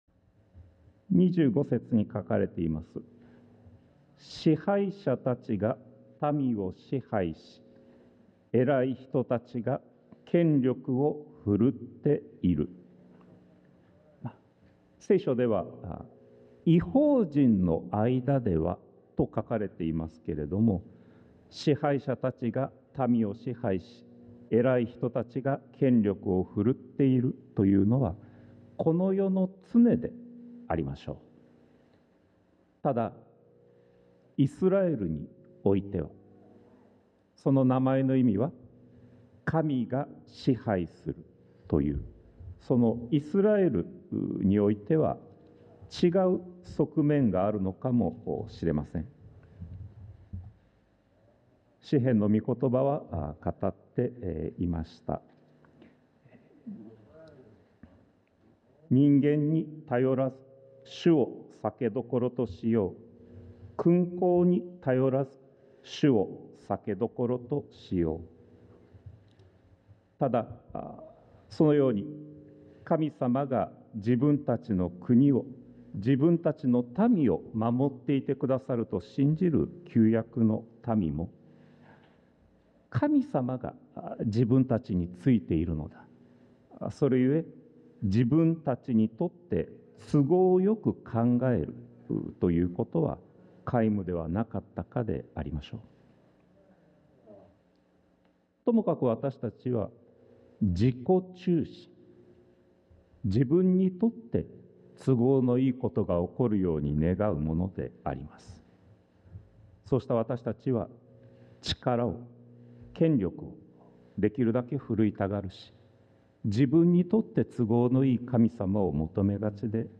sermon-2025-04-06